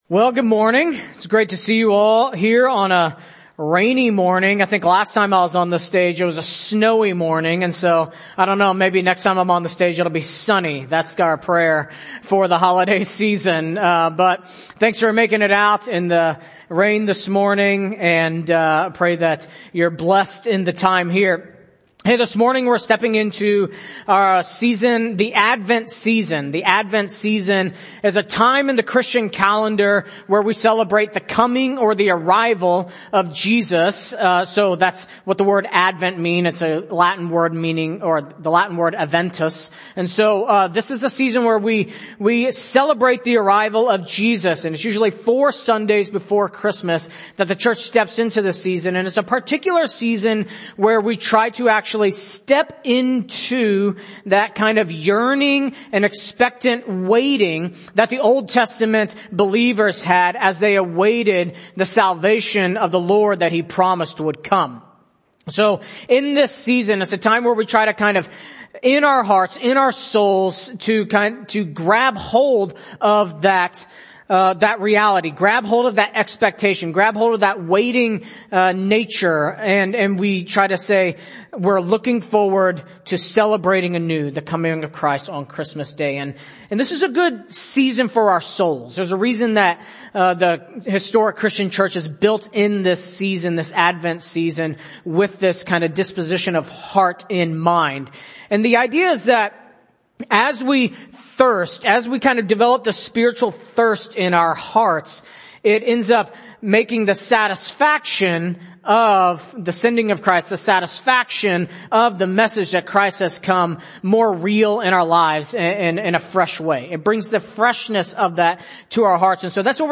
November 27, 2022 (Sunday Morning)